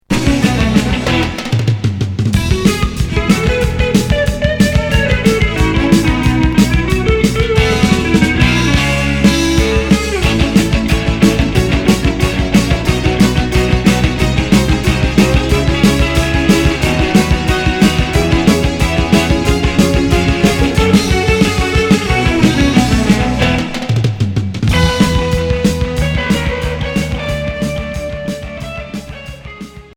Rock progressif instrumental